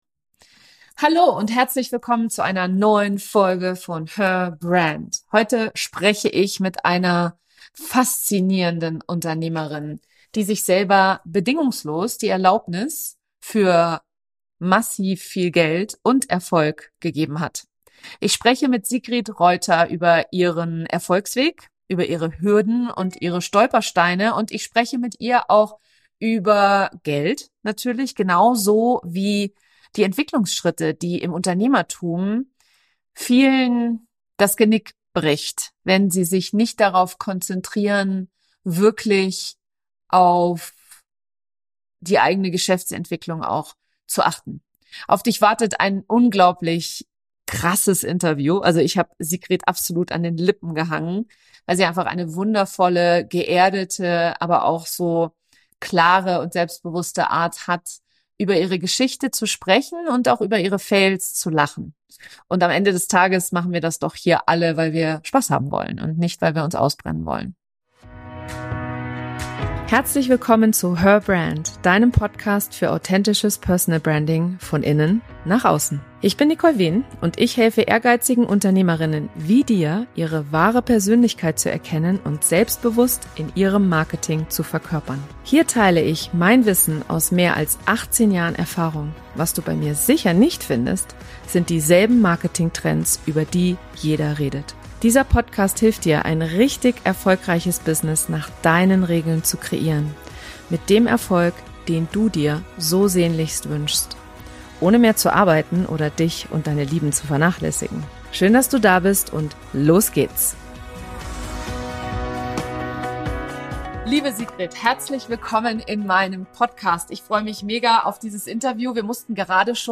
Auf dich wartet ein unglaublich krasses Interview.